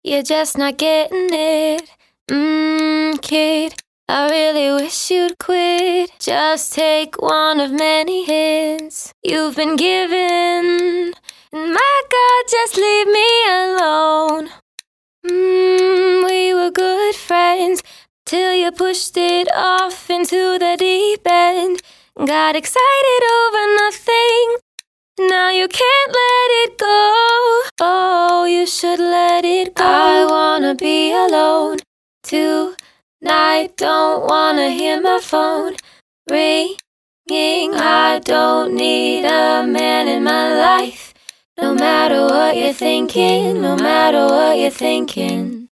除了完整的无伴奏合唱之外，您还将获得更多精美的广告素材，和声单音和短语以及最新的人声循环。